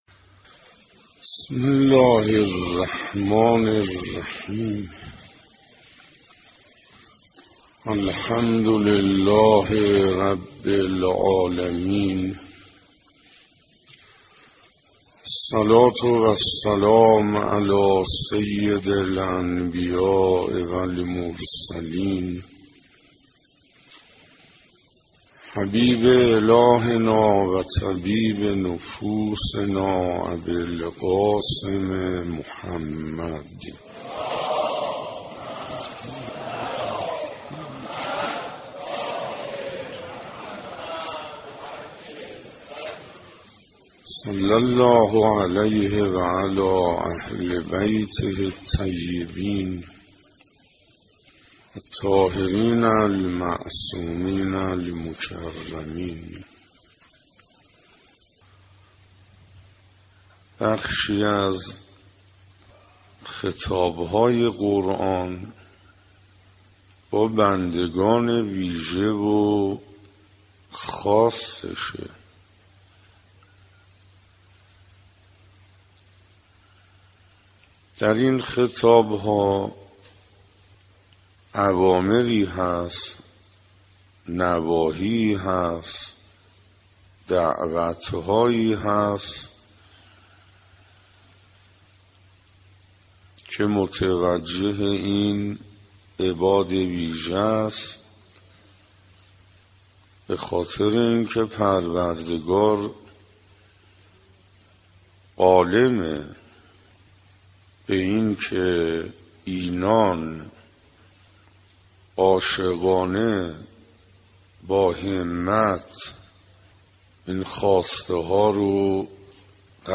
در این بخش به مناسبت فرا رسیدن ماه مبارک رمضان، هشتمین جلسه از بیانات آیت الله حسین انصاریان با عنوان «ارزشهای ماه رمضان» را تقدیم میهمانان ضیافت الهی می نماییم.